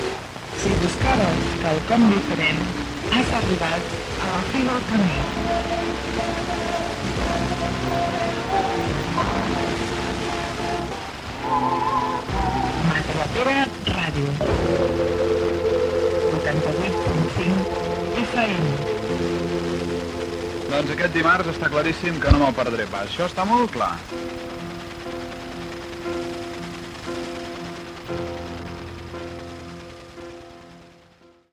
c10d6dd5b41ee7e68a50b938c096df62f41fd013.mp3 Títol Matadepera Ràdio Emissora Matadepera Ràdio Titularitat Pública municipal Descripció Identificació de l'emissora.
Qualitat de l'àudio deficient